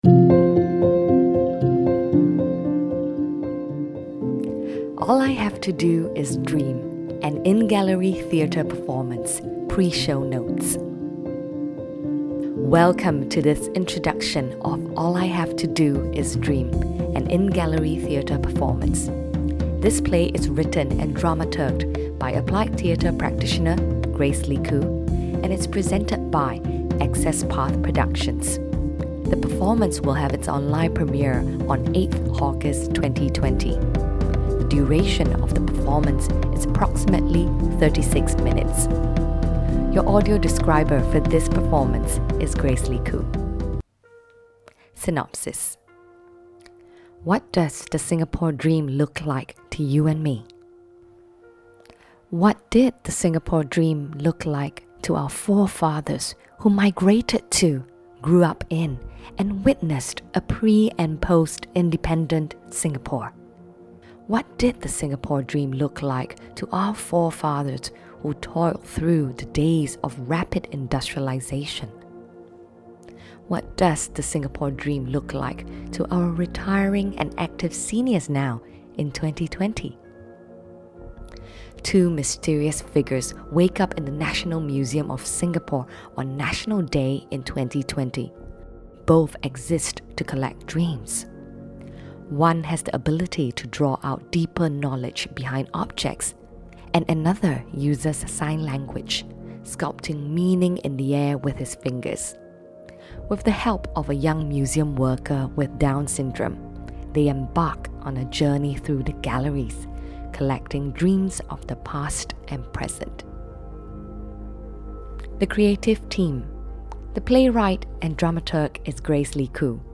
all-i-have-to-do-is-dream-audio-preshow-notes-final-final.mp3